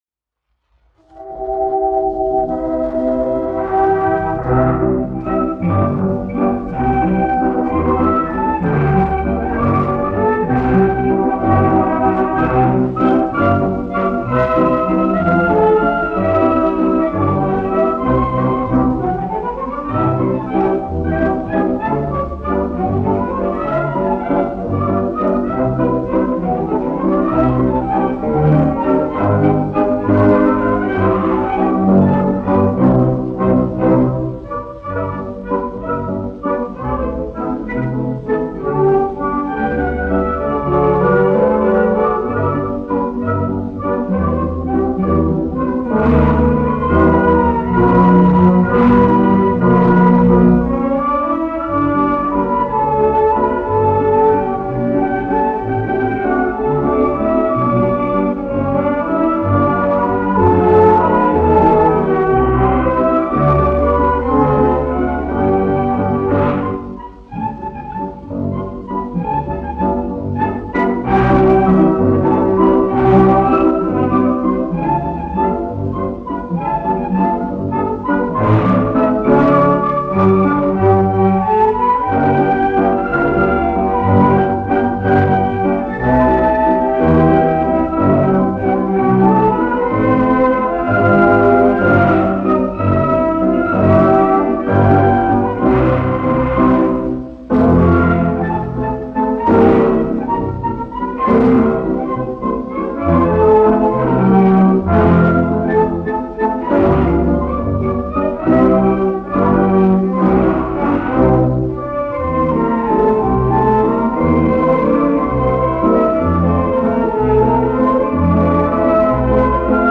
1 skpl. : analogs, 78 apgr/min, mono ; 25 cm
Valši
Orķestra mūzika
Skaņuplate